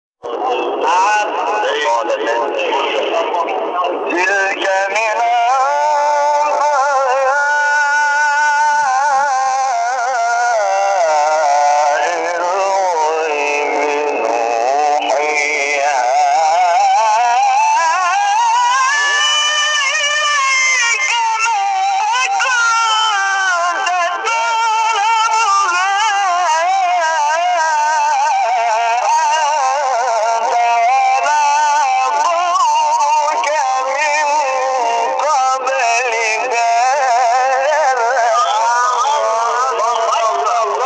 فرازی از حامد شاکرنژاد در مقام نهاوند